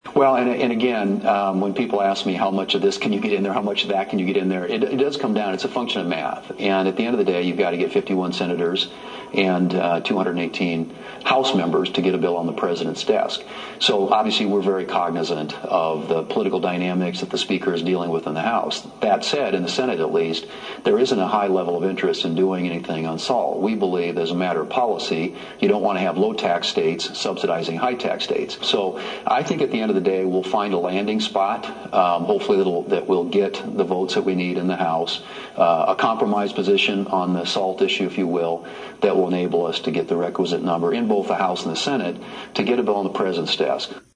WASHINGTON, D.C.(HubCityRadio)- Fox News Sunday’s host Shannon Breem did a pre-recorded interview with U.S. Senate Majority Leader John Thune which aired on Sunday.